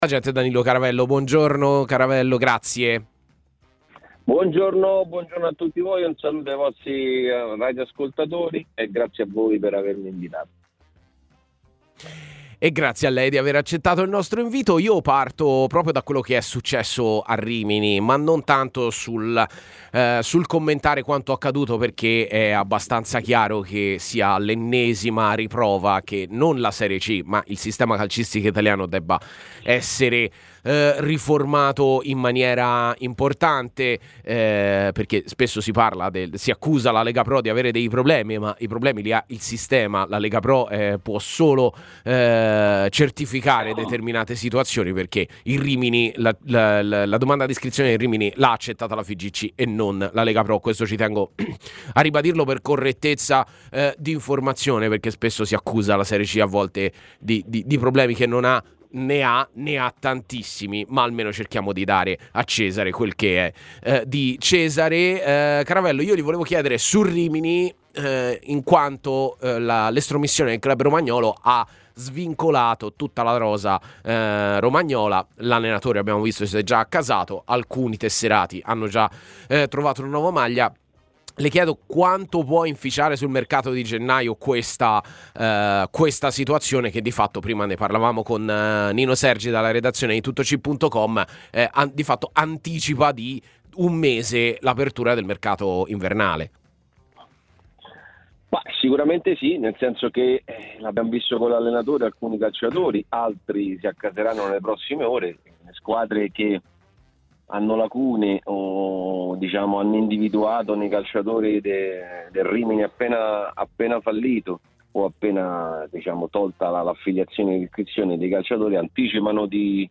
Ospite della mattinata di TMW Radio , durante la trasmissione A Tutta C